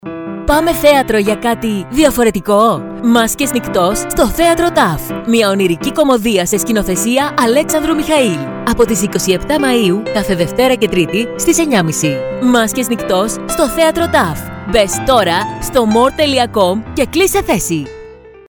Μάσκες-Νυκτός_Radio-Spot-18.mp3